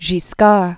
(gē-skär), Robert